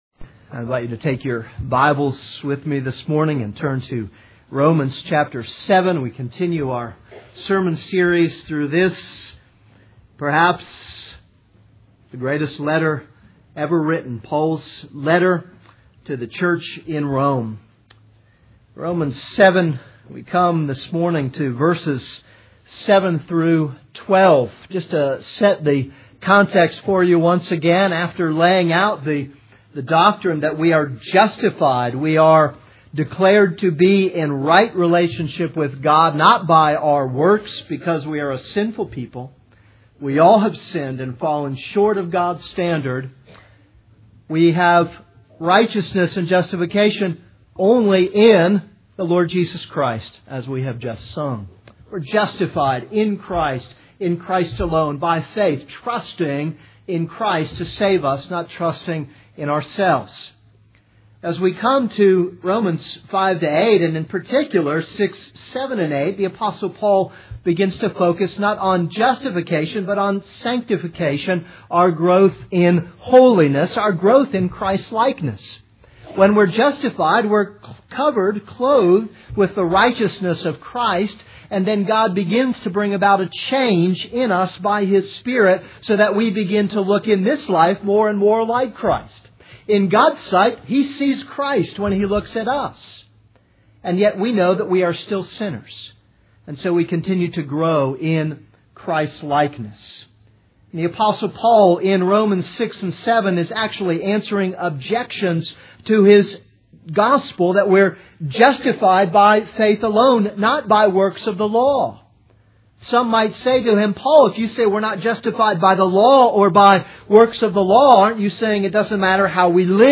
This is a sermon on Romans 7:7-12.